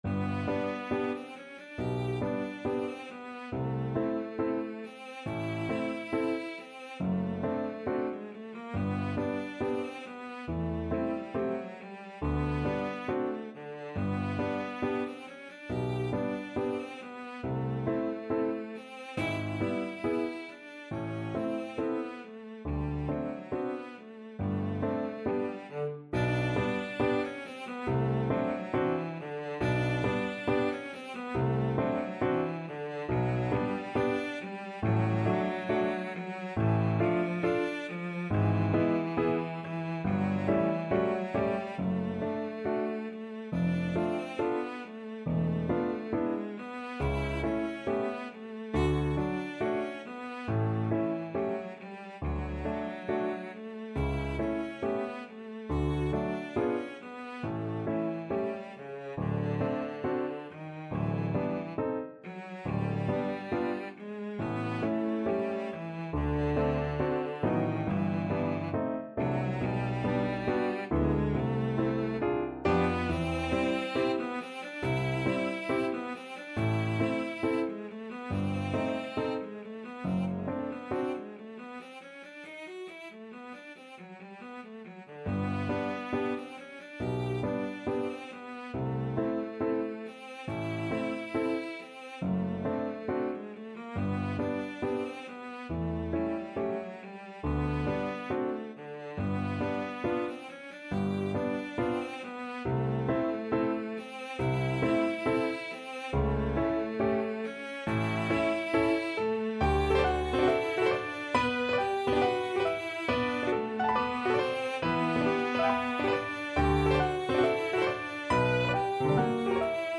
Classical Mendelssohn, Felix Spring Song, No. 6 from 'Songs Without Words' Op.62 Cello version
2/4 (View more 2/4 Music)
G major (Sounding Pitch) (View more G major Music for Cello )
~ = 69 Allegro grazioso (View more music marked Allegro)
Classical (View more Classical Cello Music)
mendelssohn_spring_song_op62_6_VLC.mp3